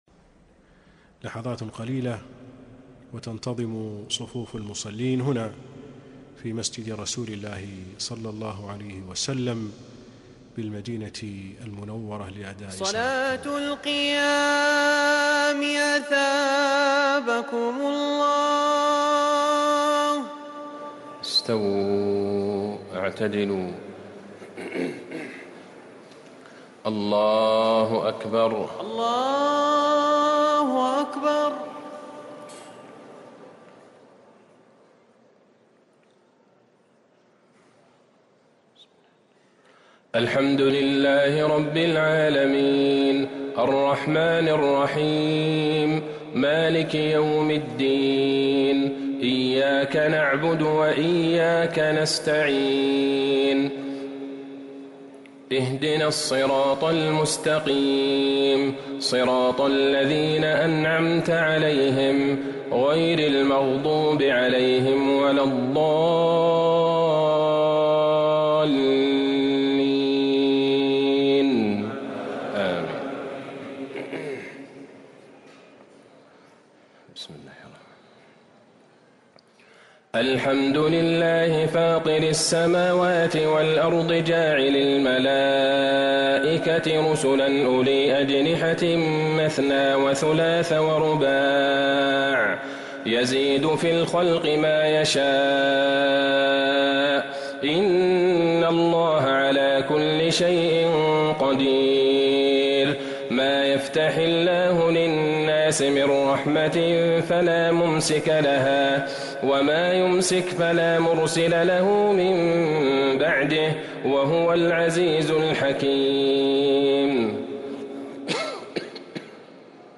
تراويح ليلة 25 رمضان 1444هـ من سورتي فاطر ويس (1-54) | taraweeh 25 st night Ramadan 1444H Surah Faatir and Yaseen > تراويح الحرم النبوي عام 1444 🕌 > التراويح - تلاوات الحرمين